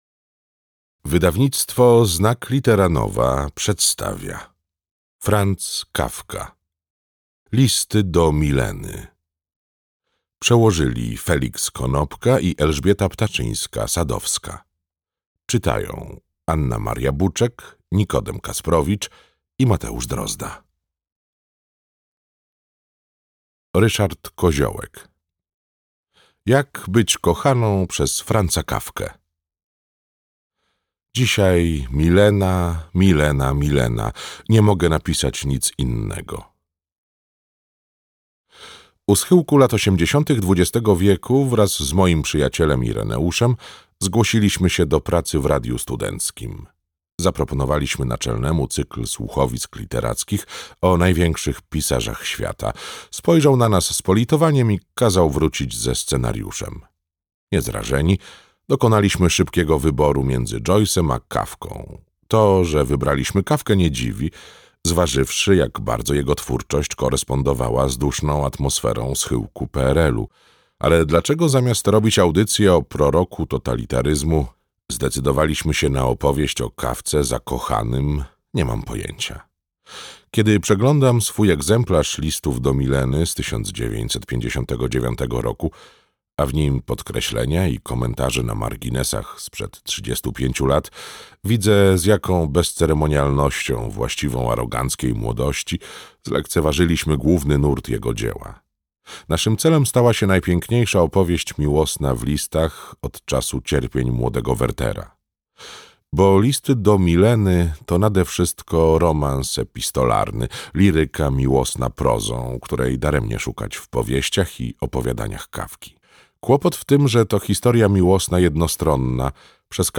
Listy do Mileny - Franz Kafka - audiobook